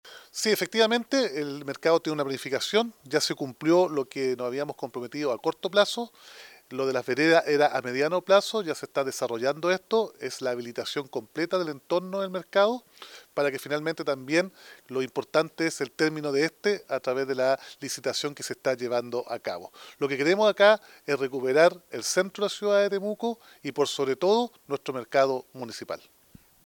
Al respecto, el alcalde de Temuco, Roberto Neira, destacó la relevancia de este proceso: “El Mercado tiene una planificación y ya se cumplió lo que nos habíamos comprometido a corto plazo.
Roberto-Neira-alcalde-Temuco-veredas.mp3